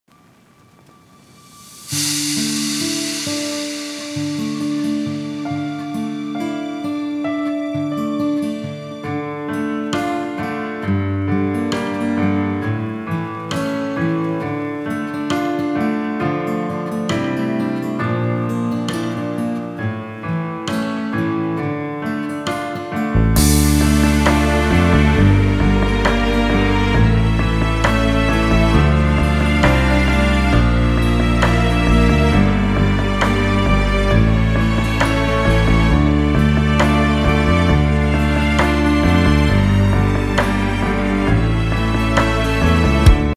Прослушать Минусовку (Демо):